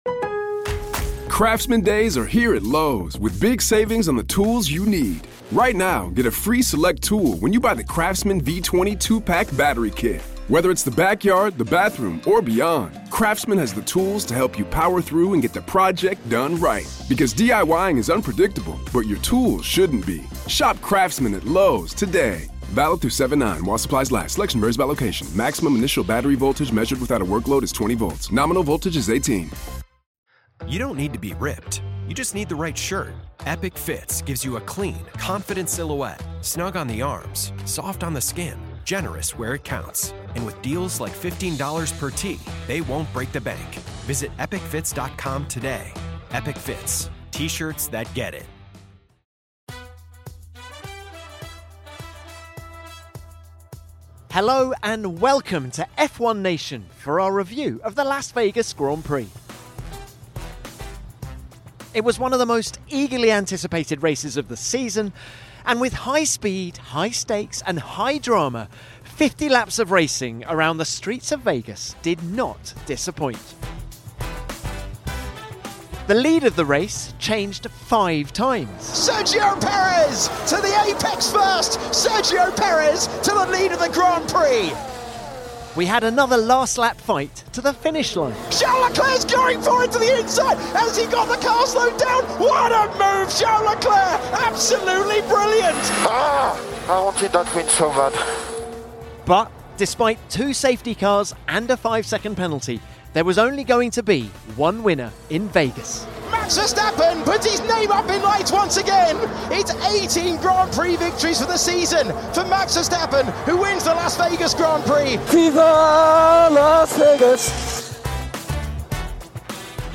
Just four points now separate Mercedes and Ferrari in the Constructors’ Championship battle for P2, so Ferrari ambassador Marc Gené and Mercedes’ Technical Director James Allison drop by to share their thoughts on who will come out on top at the final race of the season in Abu Dhabi.